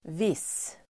okunuşu: viss